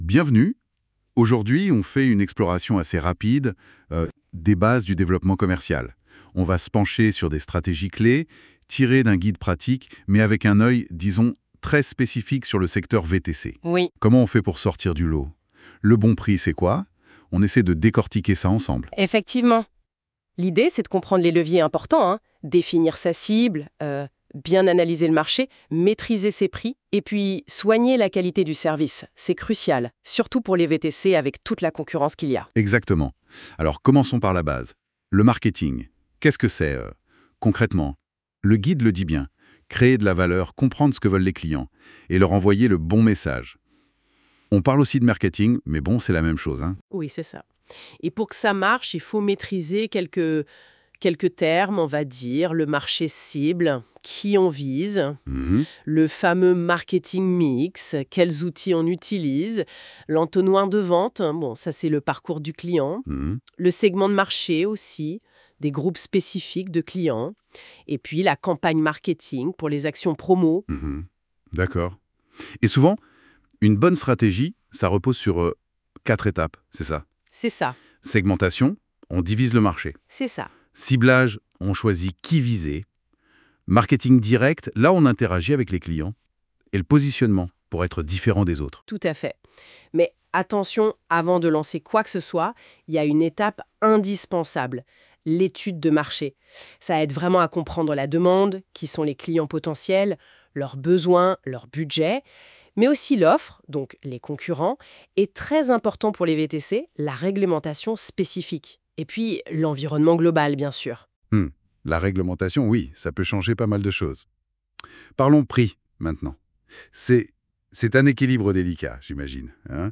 Cours audio